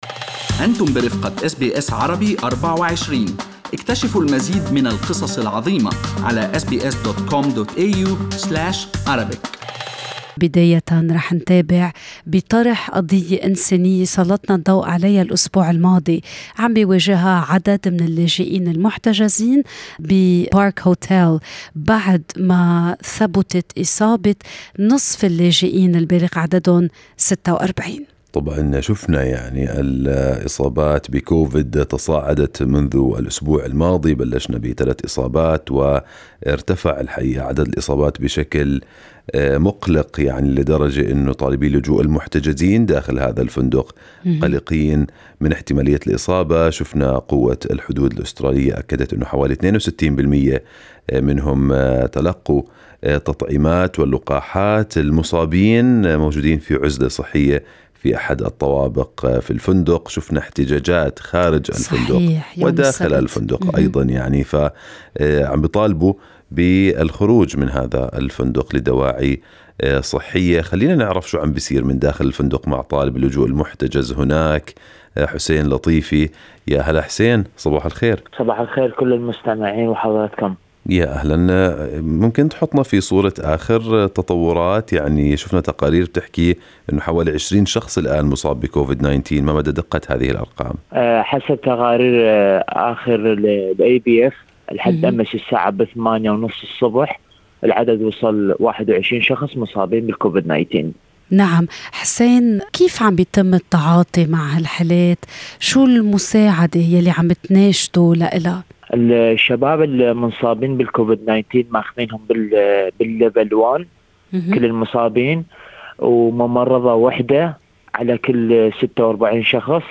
تابع برنامج Good morning Australia تطورات هذا الملف الإنساني متحدثًا مع المحتجز